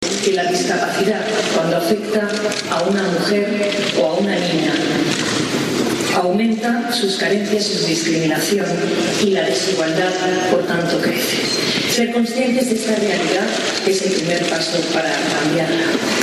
El Congreso fue clausurado por la reina Letizia, quien